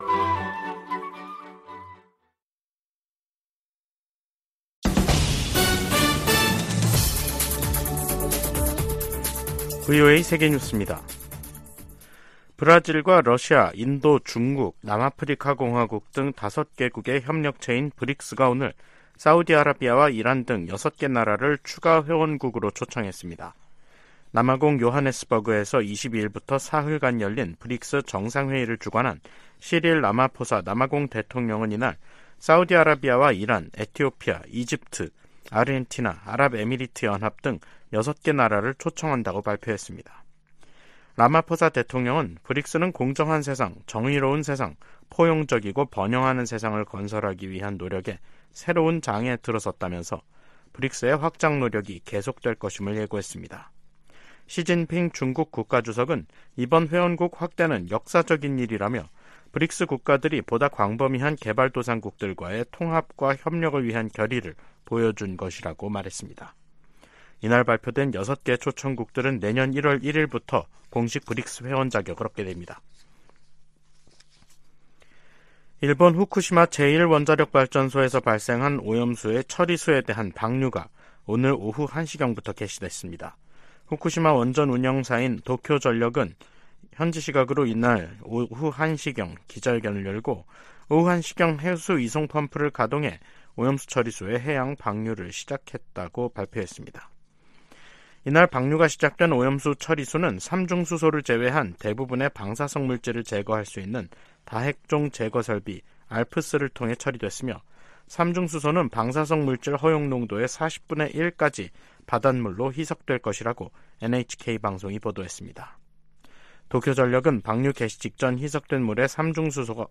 VOA 한국어 간판 뉴스 프로그램 '뉴스 투데이', 2023년 8월 24일 2부 방송입니다. 북한이 2차 군사 정찰위성 발사를 시도했지만 또 실패했습니다. 백악관은 북한 위성 발사가 안보리 결의에 위배된다고 규탄하며 필요한 모든 조치를 취할 것이라고 밝혔습니다. 미 국방부가 생물무기를 계속 개발하는 국가 중 하나로 북한을 지목했습니다.